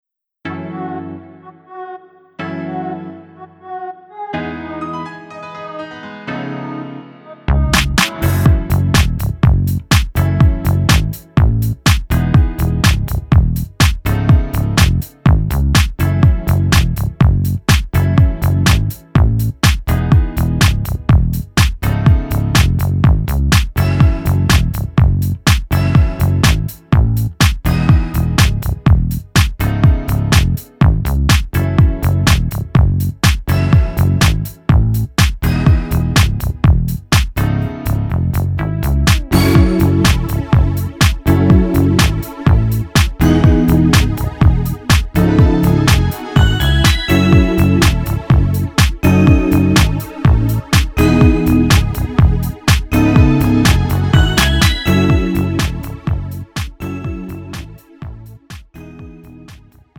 음정 남자키
장르 pop 구분 Pro MR